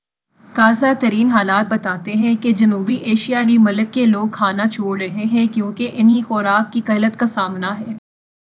deepfake_detection_dataset_urdu / Spoofed_TTS /Speaker_04 /271.wav